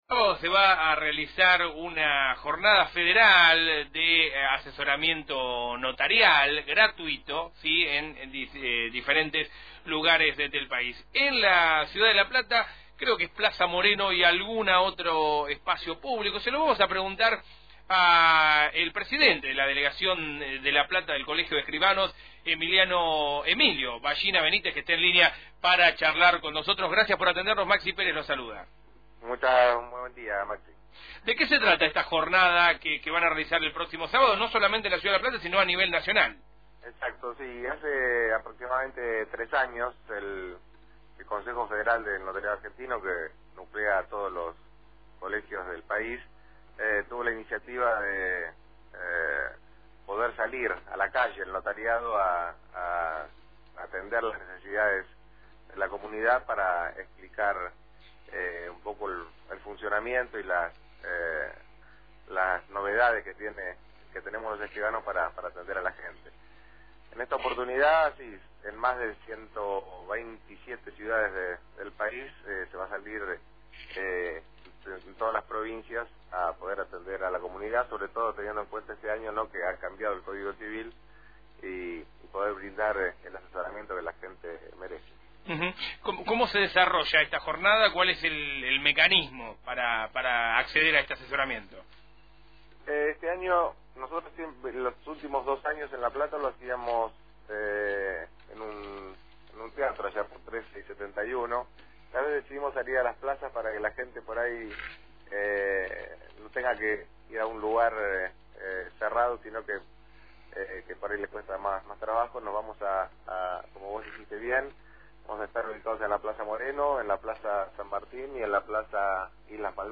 En charla con Radio Provincia